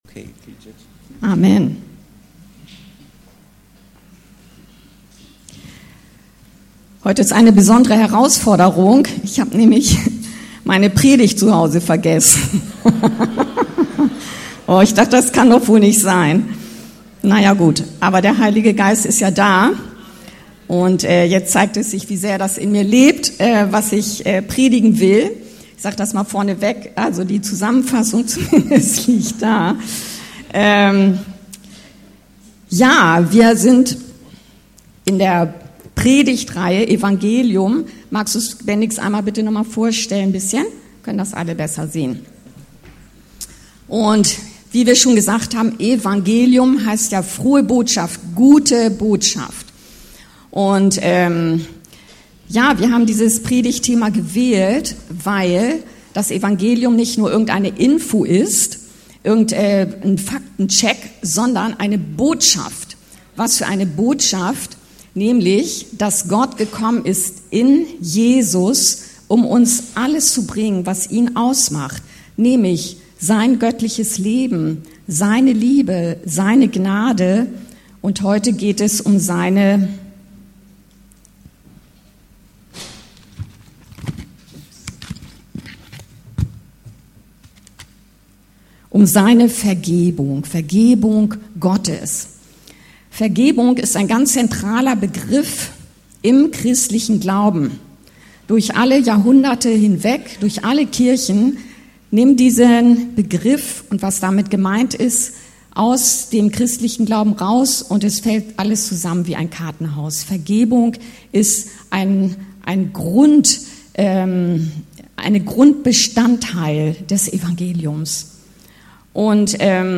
Anskar-Kirche Hamburg- Predigten Podcast